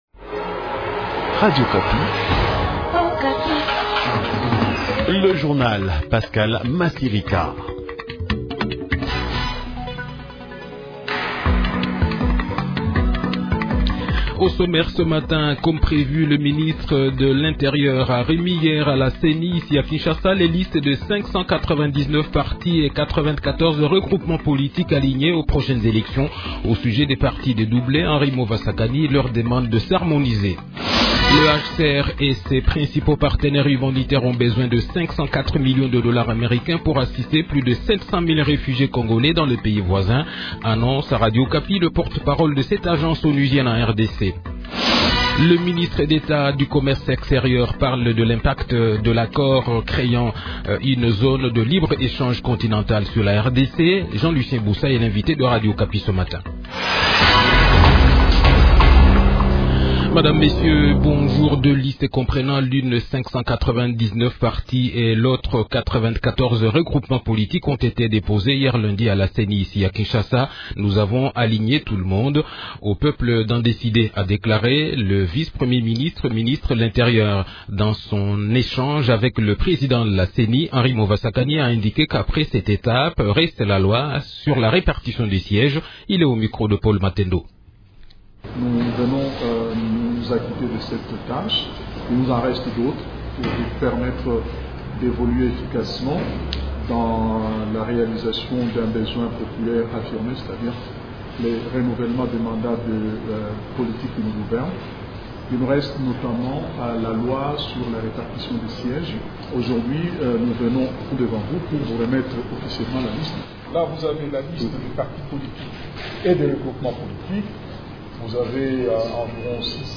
Journal Français Matin